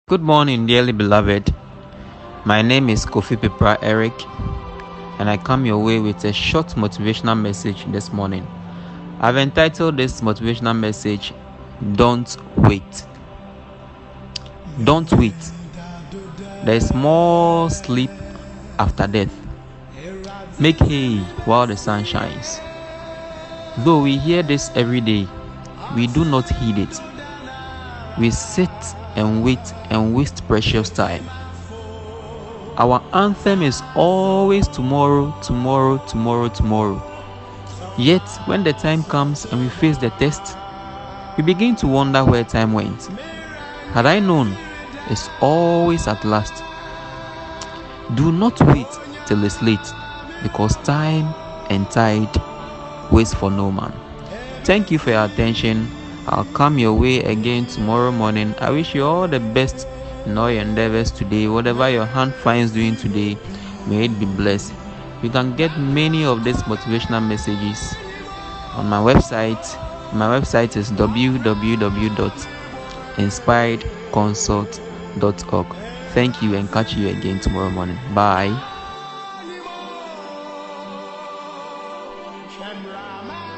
Disclaimer: I do not own the rights to the background music.